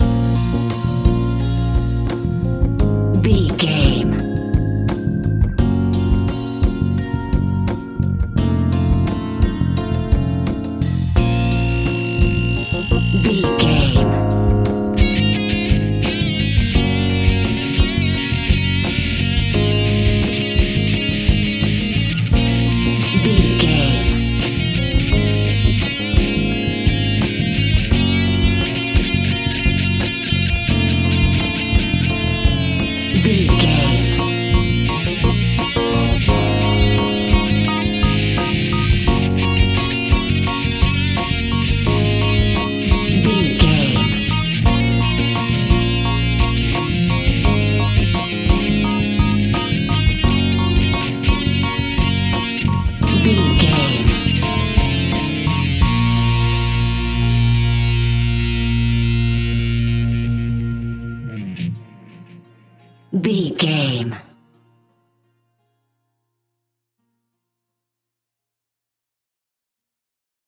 In-crescendo
Thriller
Aeolian/Minor
tension
ominous
dark
suspense
haunting
eerie
Horror Pads
Horror Synths
Horror Ambience